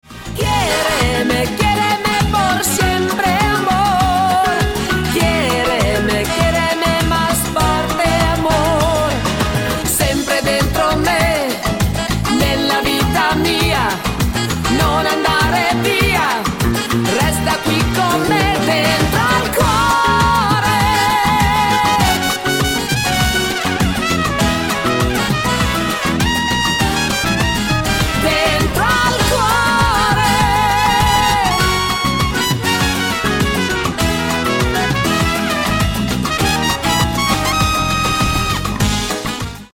SALSA  (3.21)